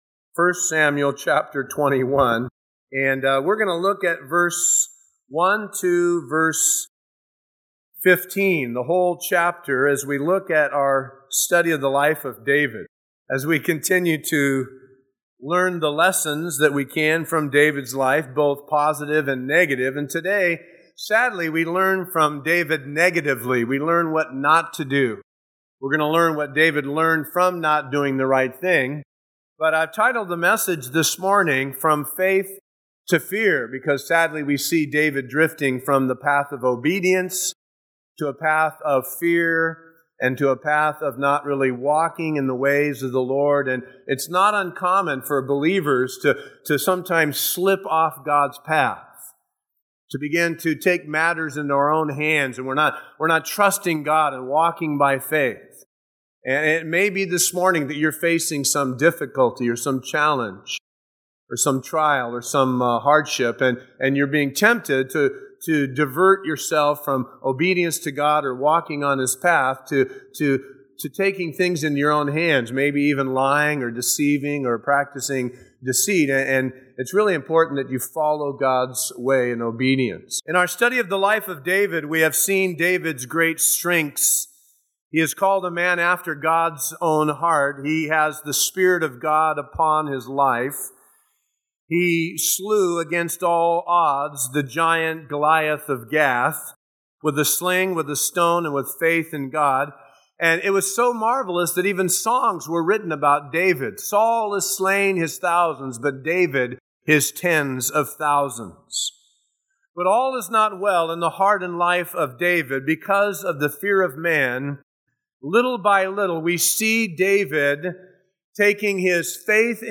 taught at Calvary Chapel San Bernardino in September 2007.